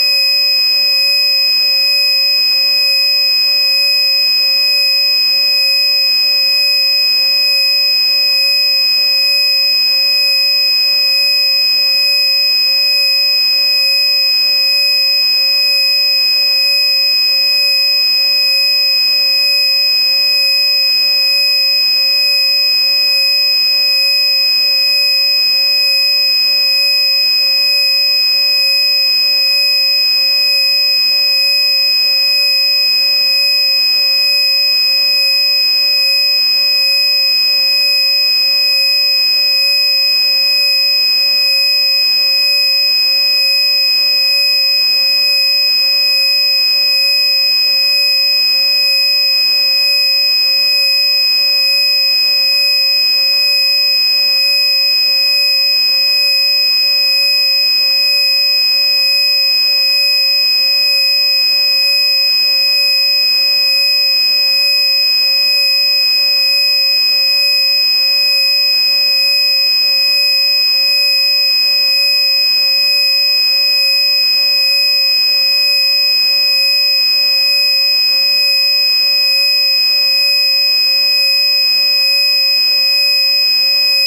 Â This is a local rendition via the computer and the ultrasound canopy is created using an ultrasound speaker, which creates the tone with a difference (not as it is heard normally as a tone but via a tinnitus tone) as it permeates the space and engulfs you.
recording audacity home set up 3 nov 2015